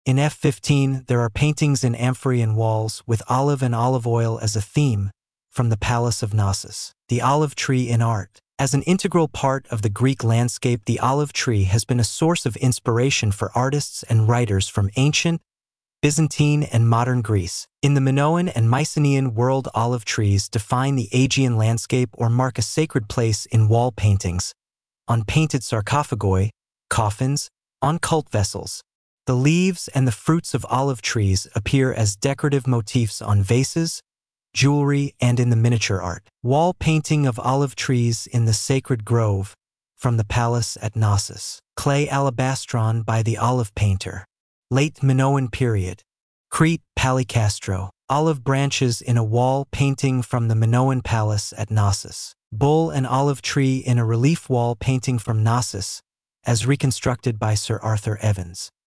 Audio guided tour